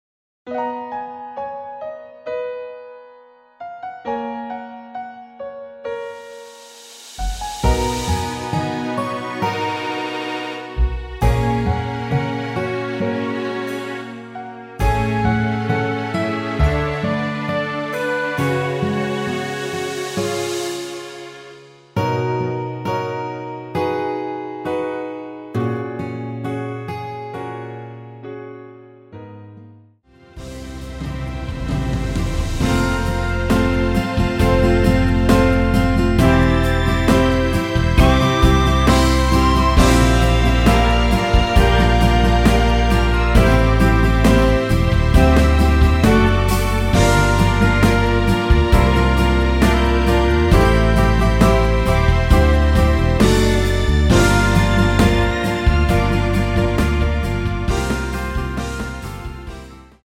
원키에서(-8)내린 MR입니다.
F#
앞부분30초, 뒷부분30초씩 편집해서 올려 드리고 있습니다.
중간에 음이 끈어지고 다시 나오는 이유는